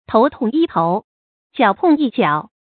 tóu tòng yī tóu，jiǎo tòng yī jiǎo
头痛医头，脚痛医脚发音